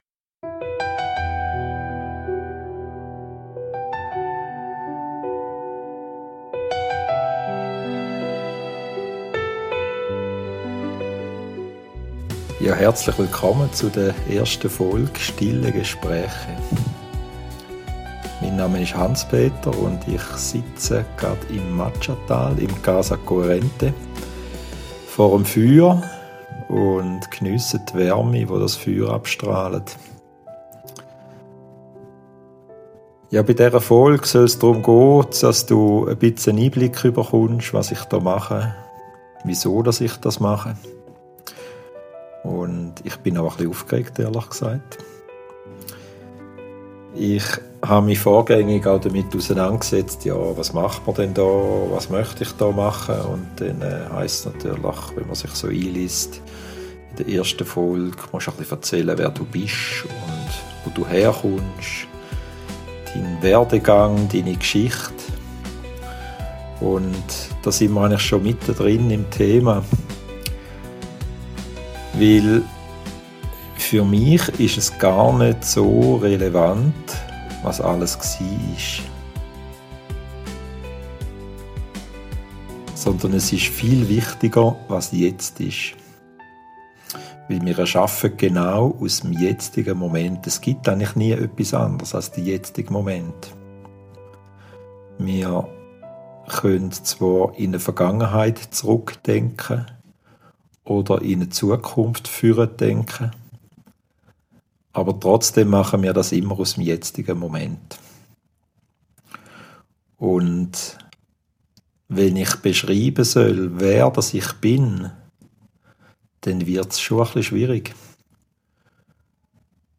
Schweizerdeutsch gesprochen. In der ersten Episode von "Stille Gespräche" erzähle ich dir, wer ich in meiner tiefen Essenz wirklich bin und warum ich diesen Podcast mache. Vom Perfektionisten zum Burnout.